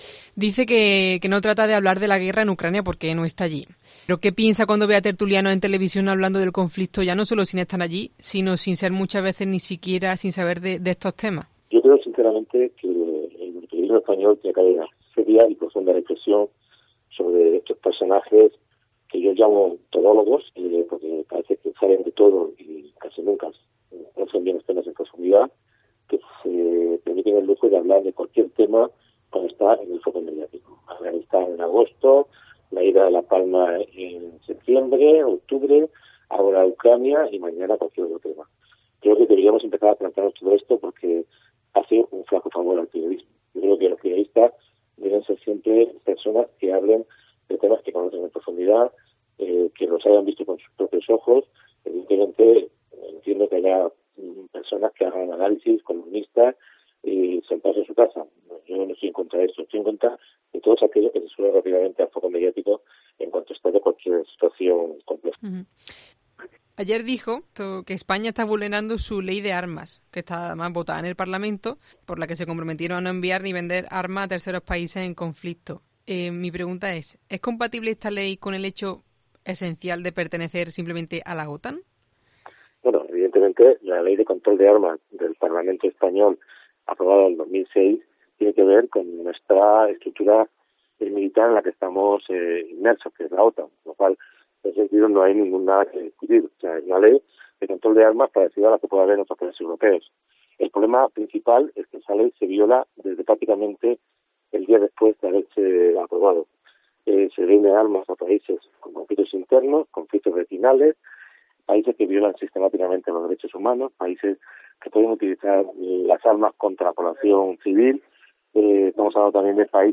Escucha la entrevista completa a Gervasio Sánchez, fotoperiodista cordobés en zonas de conflicto